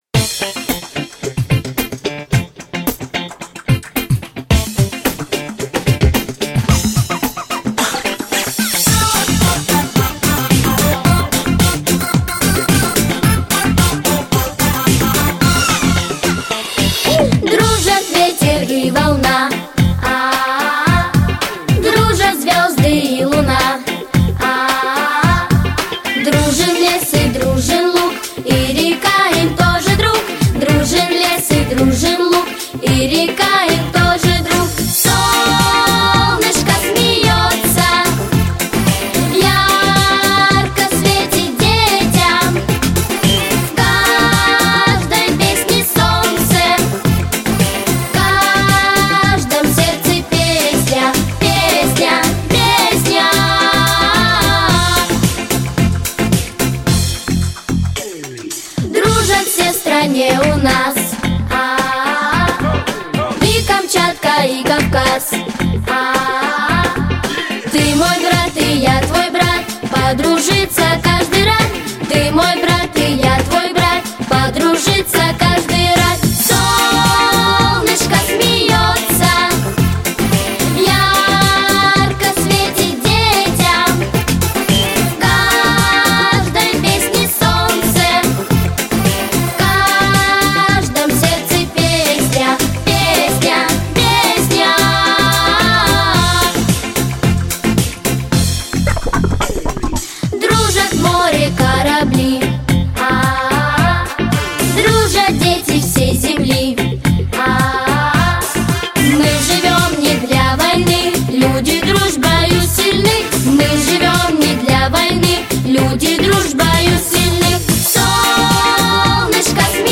• Категория: Детские песни
Детская эстрадная вокальная студия.